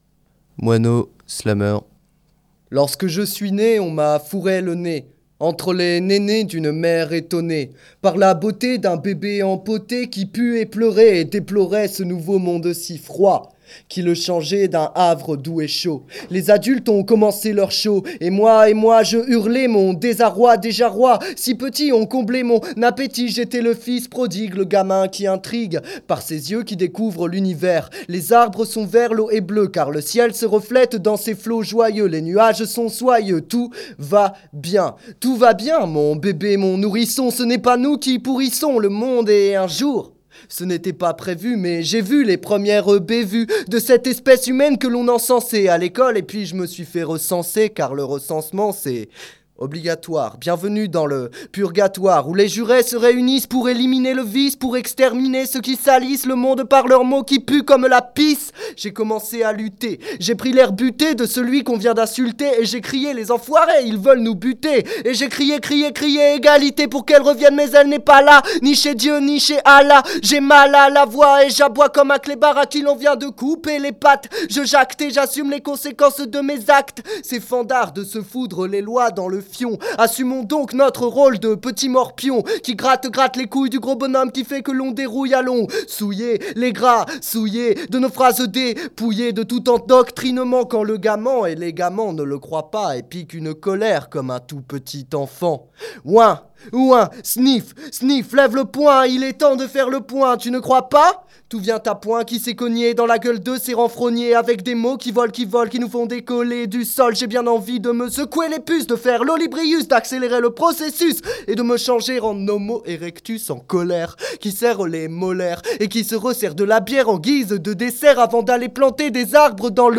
ateliers slam , écriture et enregistrement de séquences
séquence slam 12